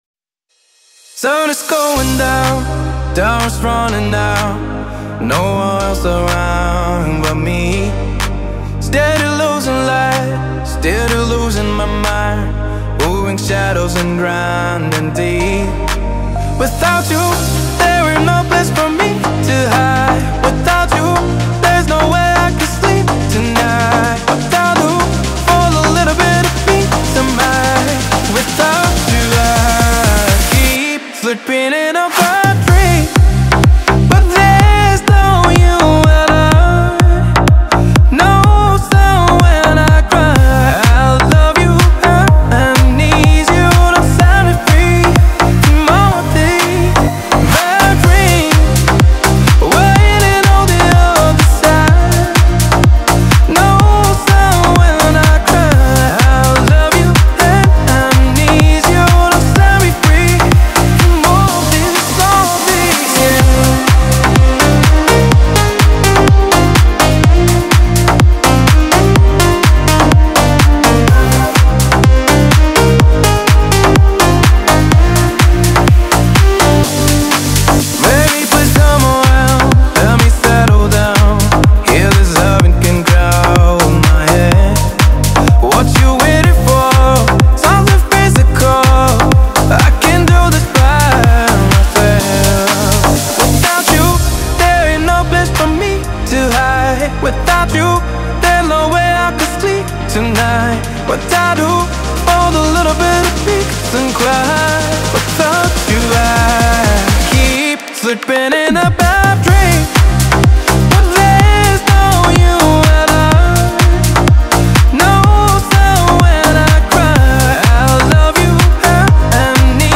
известный своими экспериментами в жанре электронной музыки